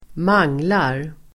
Ladda ner uttalet
manglar.mp3